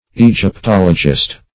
Definition of egyptologist.
Egyptologist \E`gyp*tol"o*gist\